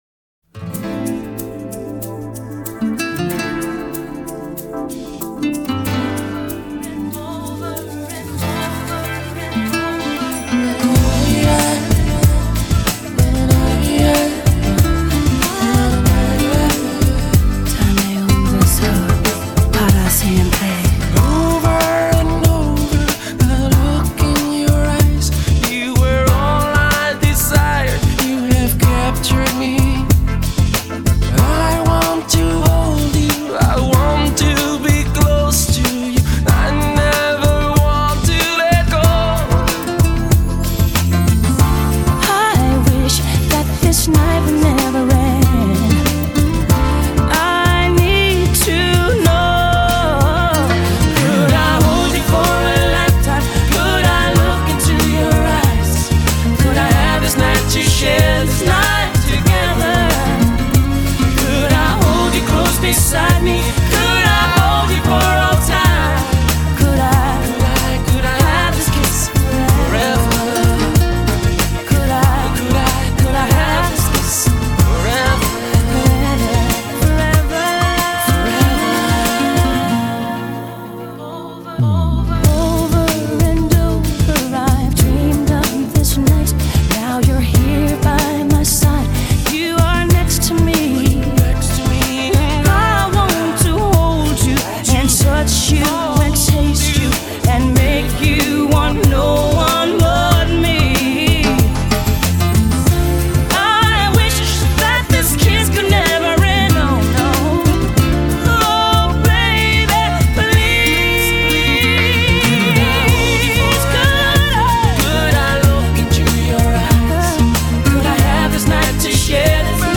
Еще один прекрасный дуэт